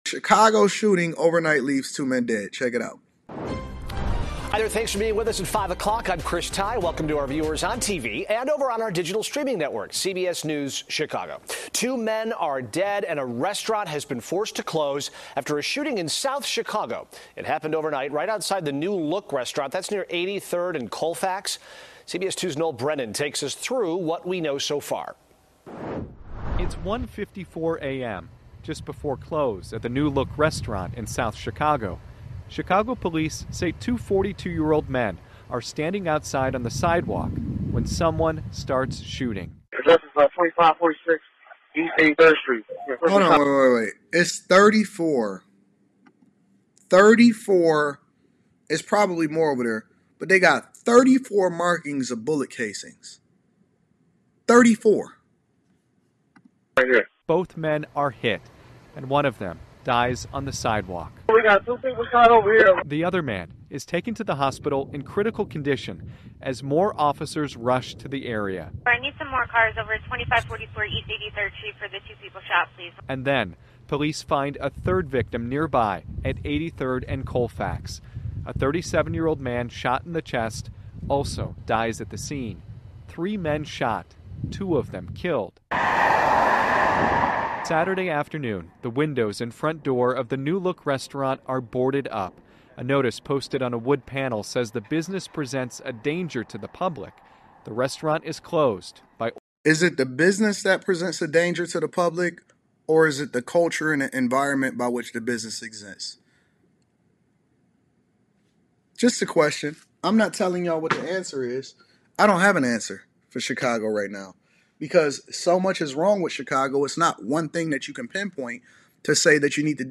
MP3 Audio Summary